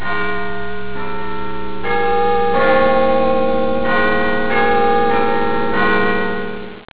Bigben.wav